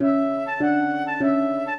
flute-harp
minuet5-2.wav